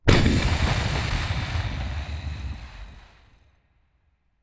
SmokeBomb.wav